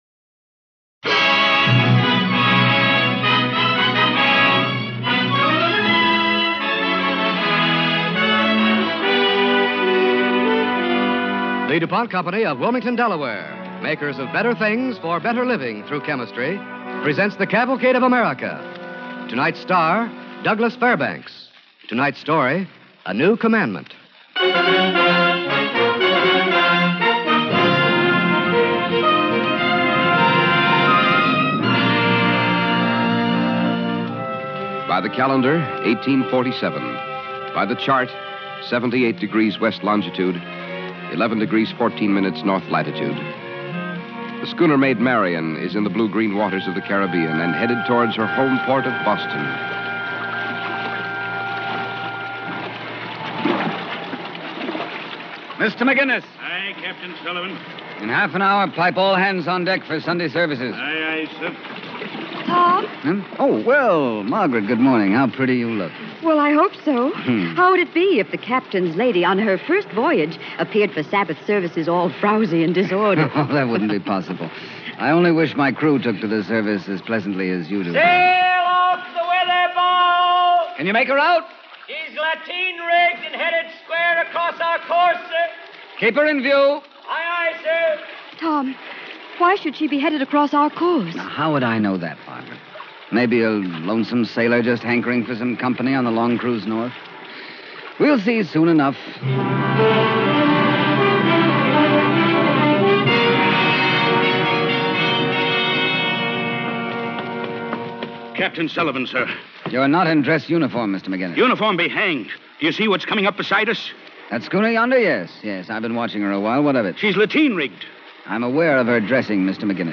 starring Douglas Fairbanks Jr. and Gerttude Warner
Cavalcade of America Radio Program